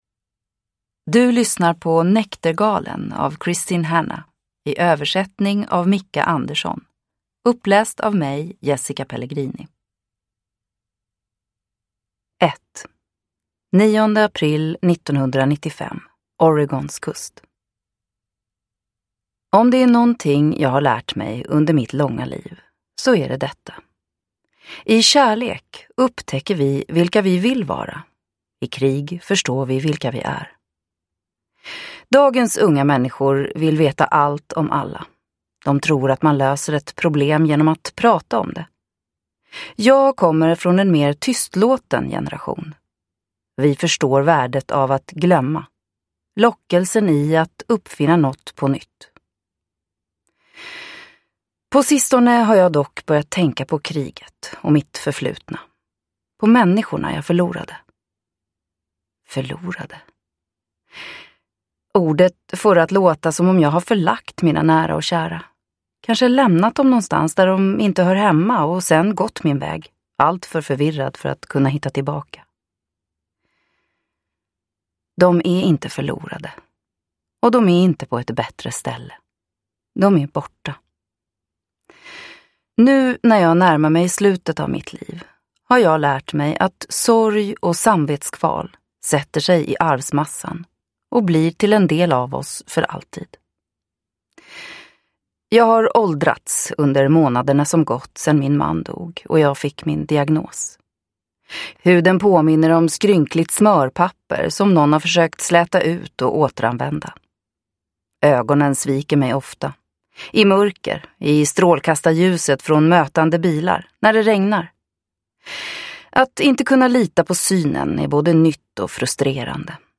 Näktergalen (ljudbok) av Kristin Hannah